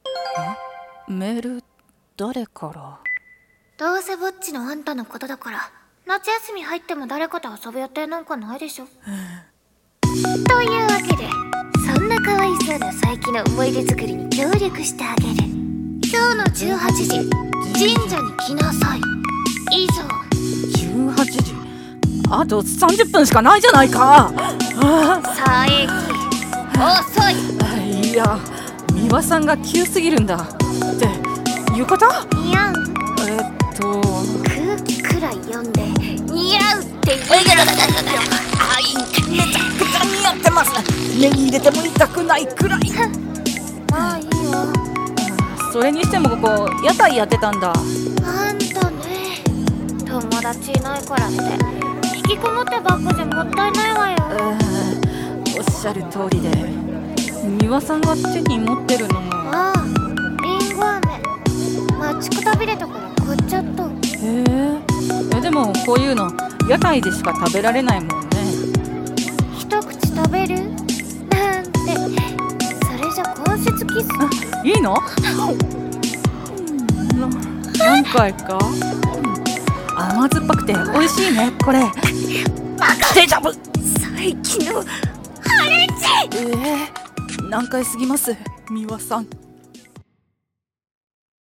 二人声劇【難解すぎます！美羽さん！！-縁日ver-】